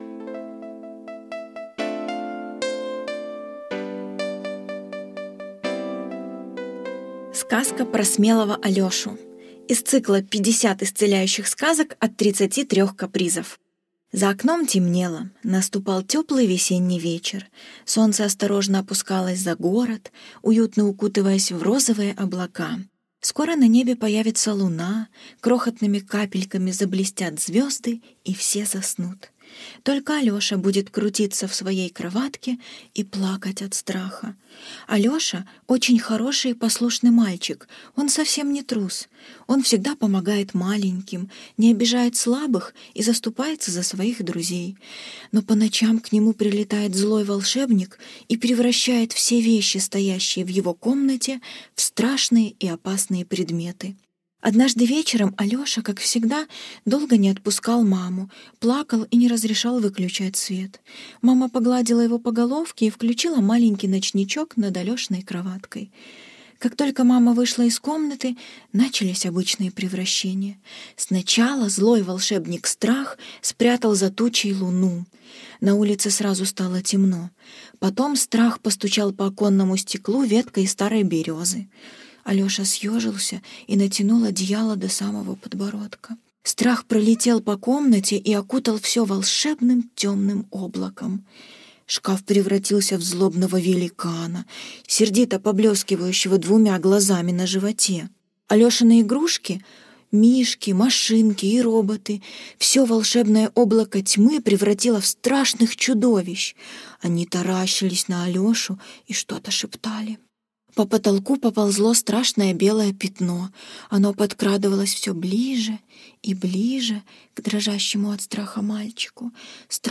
Сказка про смелого Алешу - аудиосказка Туриной - слушать онлайн